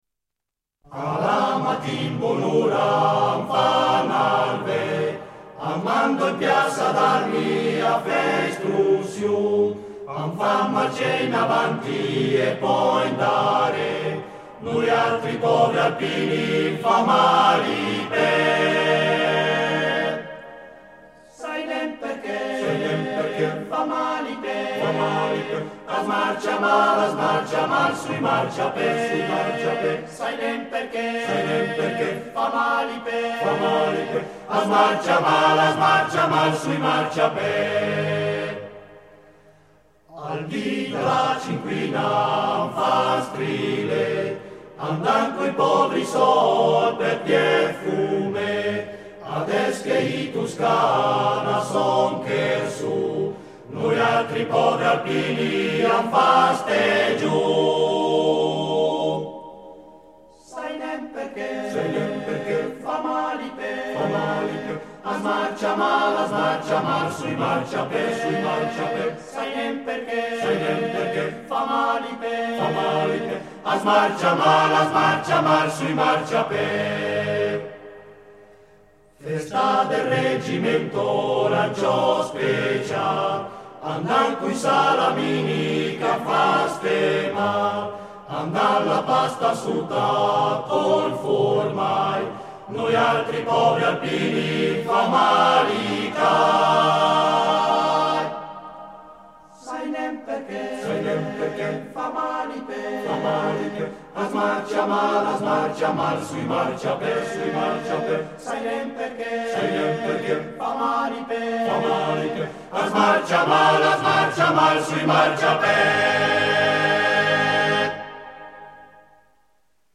Esecutore: Coro Edelweiss
Fa parte di: Coro Edelweiss e Ensemble Vocal Andrézieux-Bouthéon - 20 maggio 1995